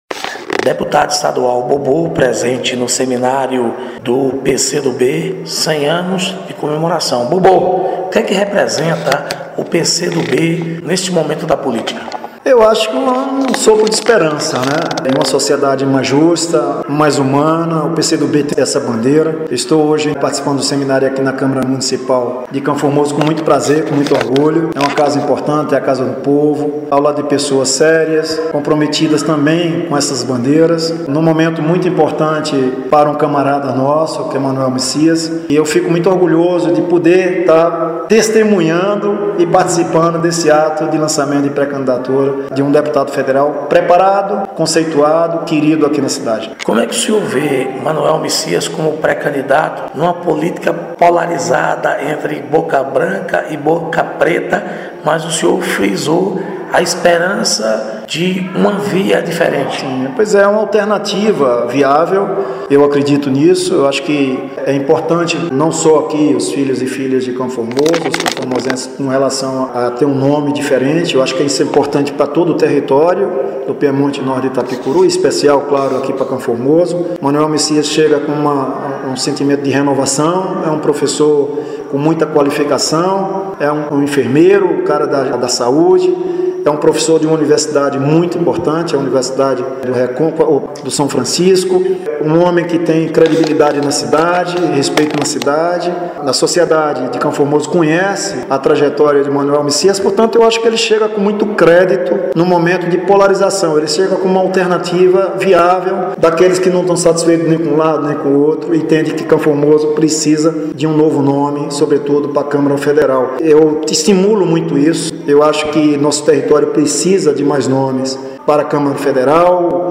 Reportagem: PCdoB – seminário de 100 anos na Câmara municipal de CF